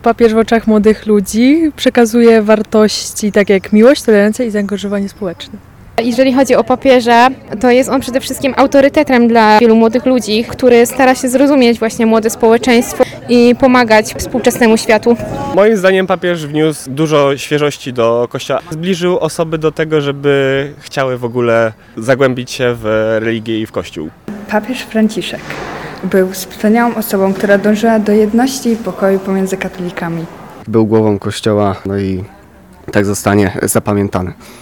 Tarnowscy studenci wspominając papieża Franciszka podkreślają, że był człowiekiem, który swoją posługą zbliżał ludzi do Boga.